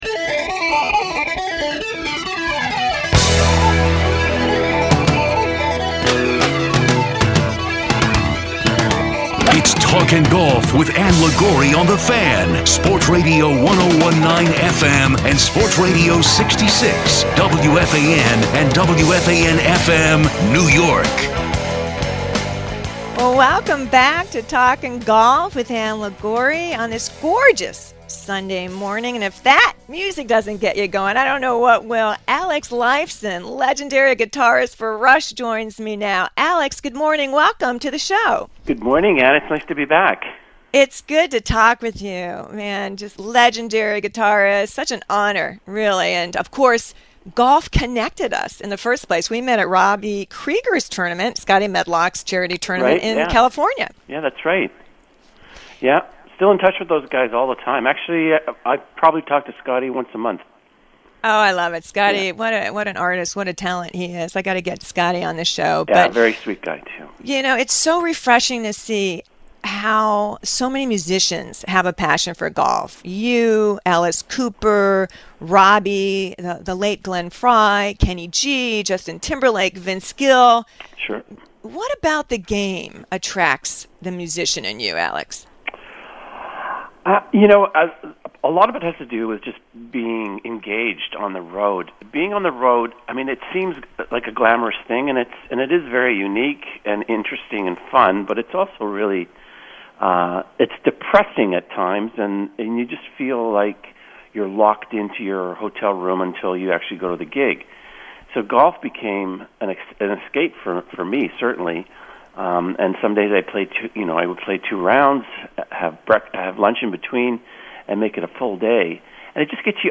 Facebook Twitter Headliner Embed Embed Code See more options Ann has a revealing chat with Alex Lifeson, legendary guitarist, co-founder of RUSH and passionate golfer, about the similarities between playing guitar and learning golf, fun golf stories while on tour, and he opens up about how the death of iconic RUSH drummer Neil Peart affected him and whether he and Geddy Lee will ever get back on stage together.